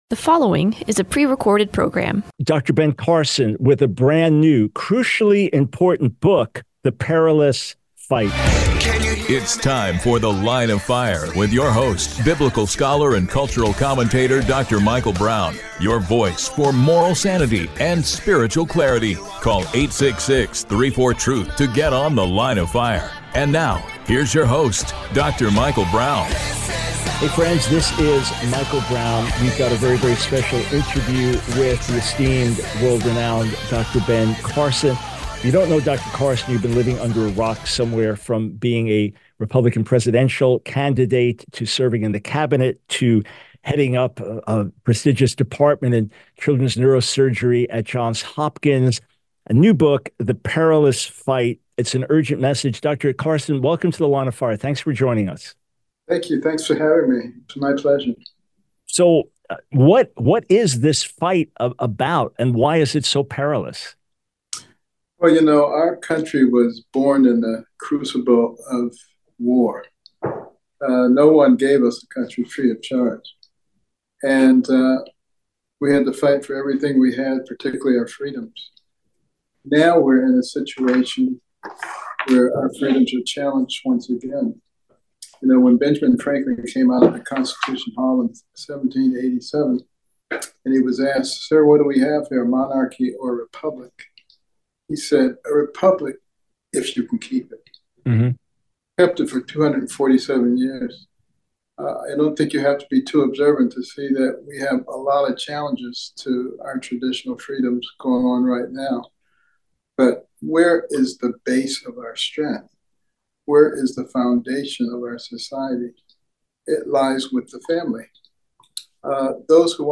The Line of Fire Radio Broadcast for 10/14/24.